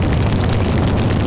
flame_loop.wav